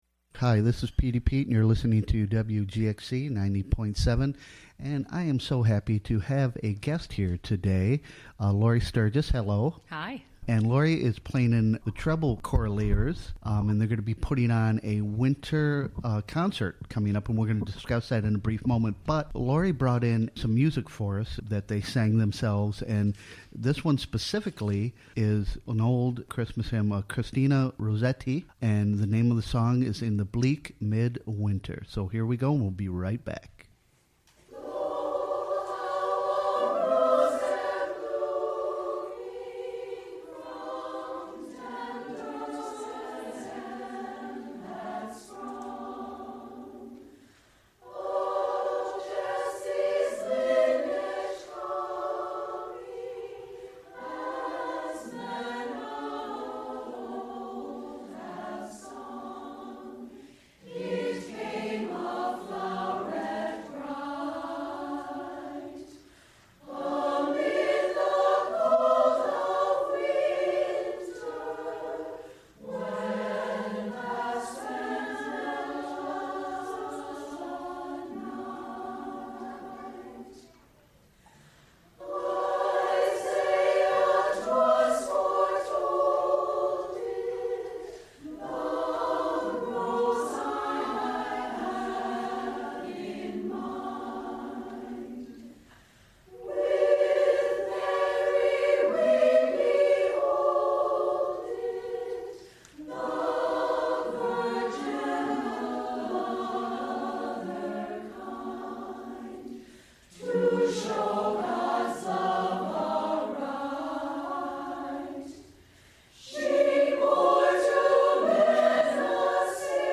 Recorded live at the Catskill Library during the WGXC Morning Show.